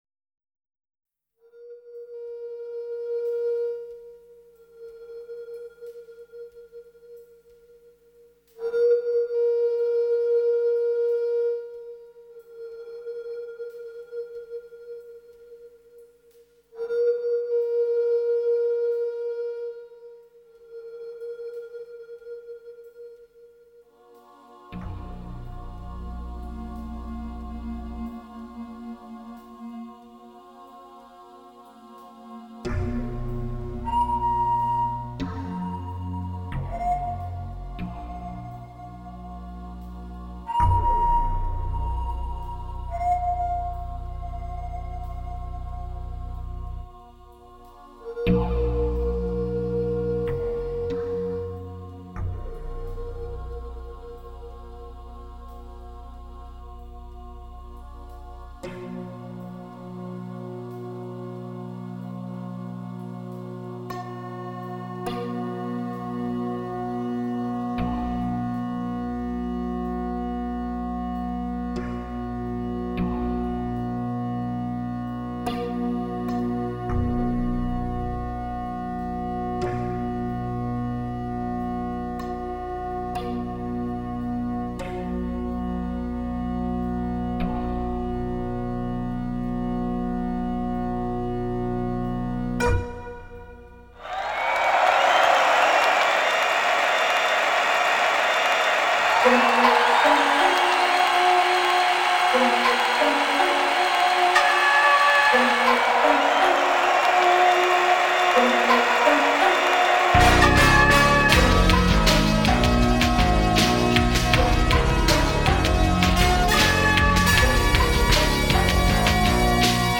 ALL INSTRUMENTAL SOLO PROJECTS